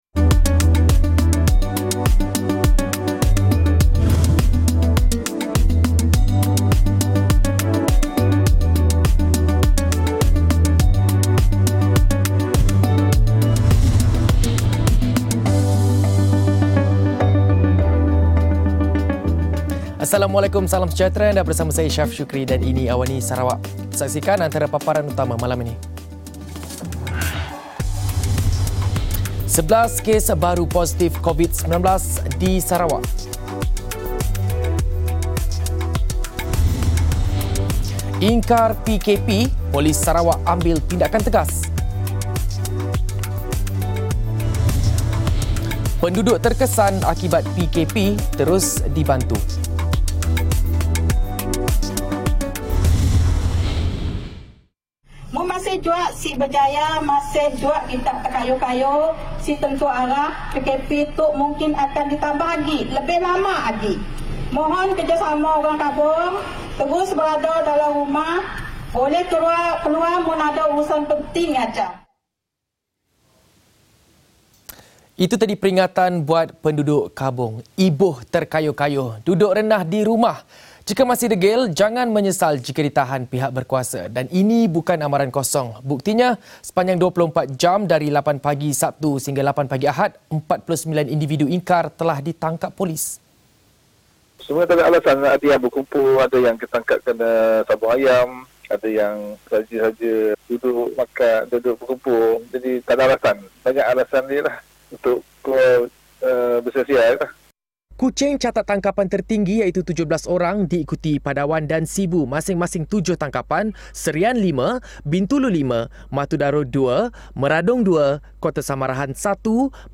Laporan berita ringkas dan padat